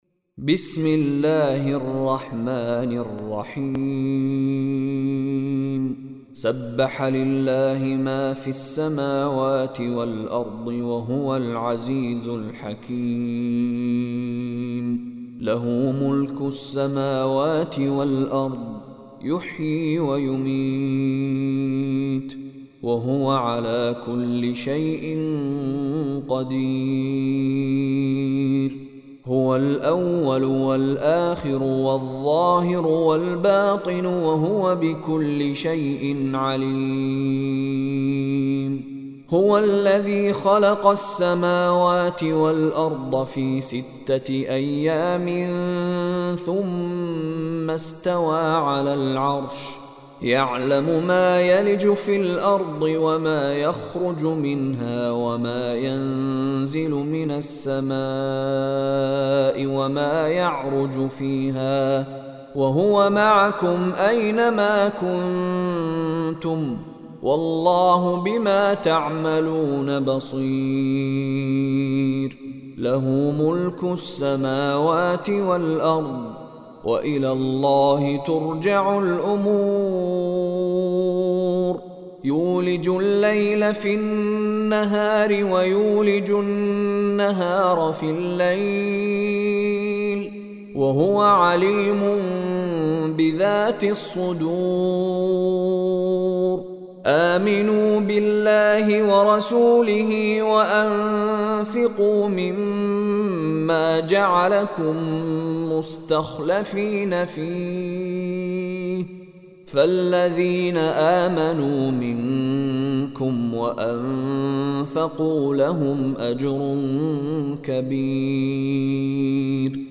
Surah Al Hadid Recitation by Mishary Rashid
Surah Al-Hadid is 57th chapter or surah of Holy Quran. It has 29 verses or ayats. Surah Al-Hadid recited in Arabic listen online mp3 or download audio, recited by Sheikh Mishary Rashid Alafasy.